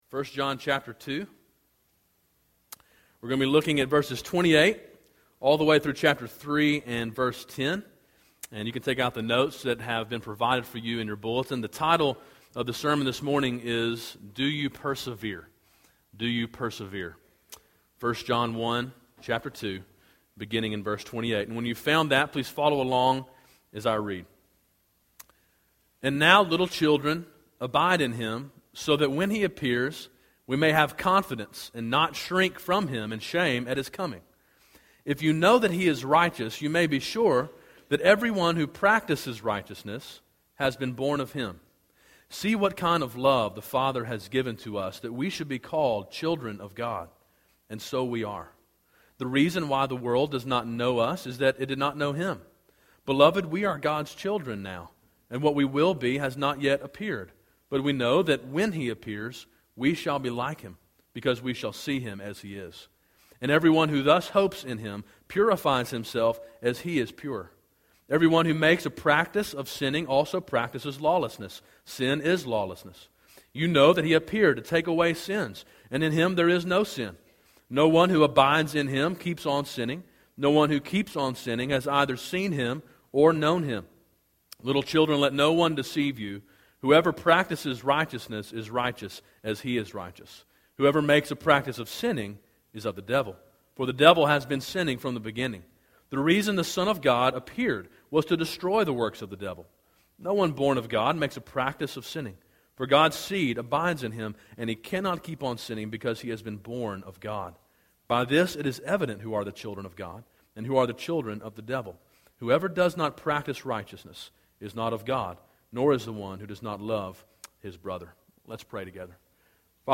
A sermon in a series on the book of 1 John.